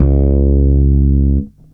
15-C#2.wav